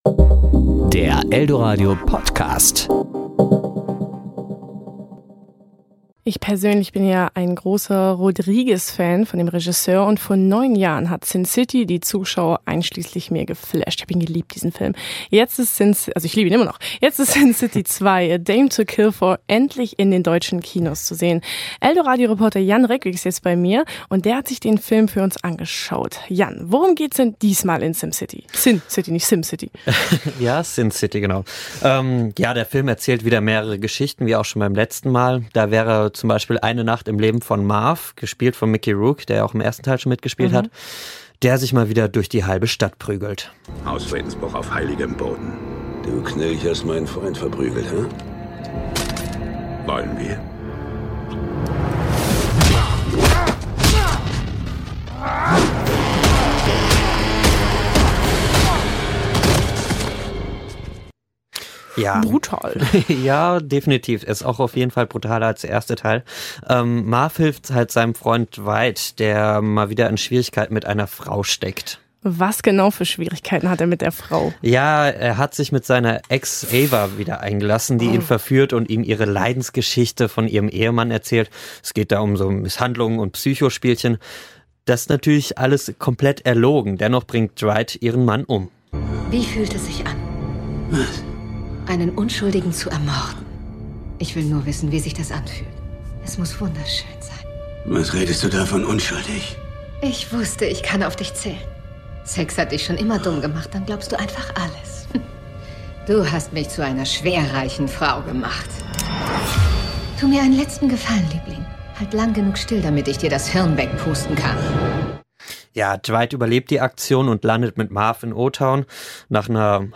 Serie: Kollegengespräch  Ressort: Kino